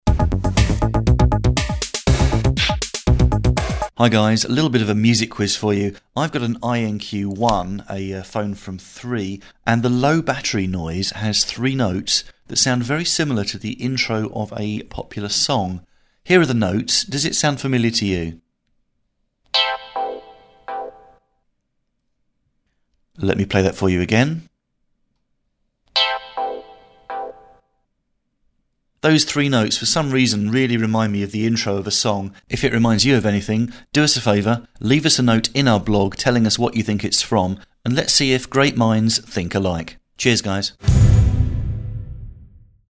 The FrequencyCast phone, the INQ1 has a distinctive 3 note “Low Battery” tone that wafts through FrequencyCast HQ and reminds me of a certain UK song.